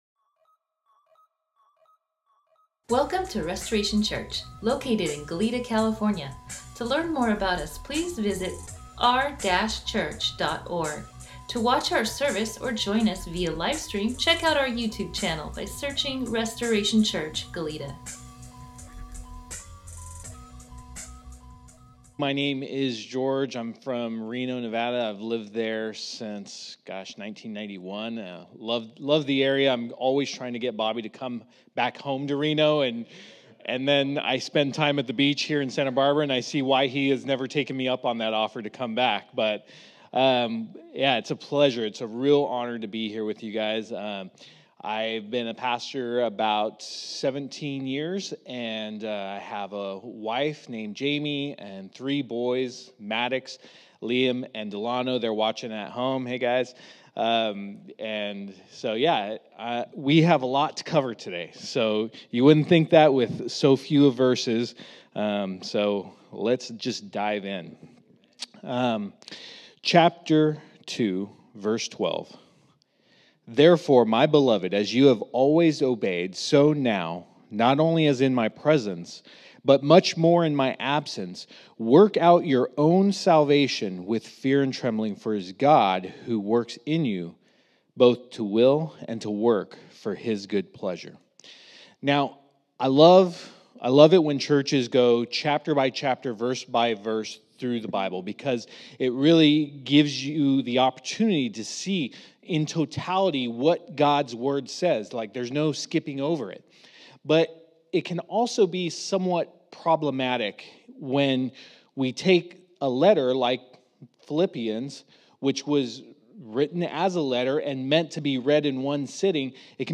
Sermon NotesDownload Thanks for checking us out today.